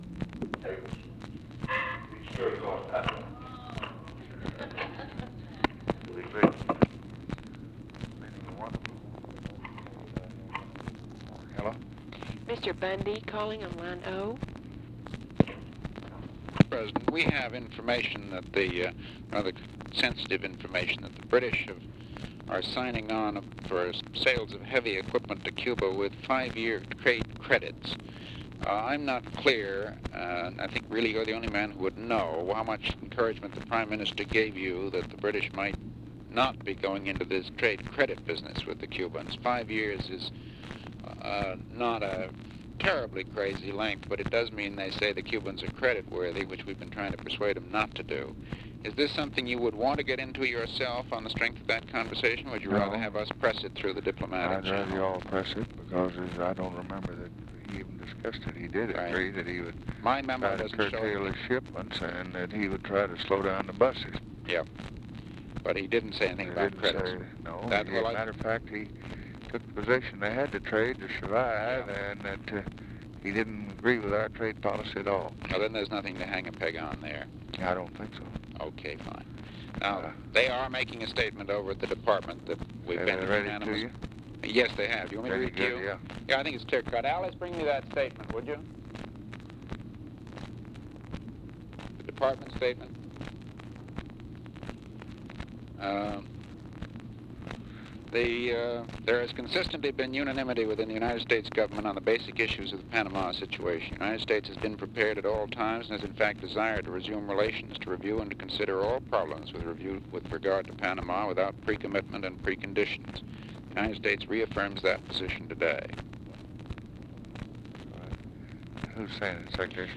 Conversation with MCGEORGE BUNDY, March 19, 1964
Secret White House Tapes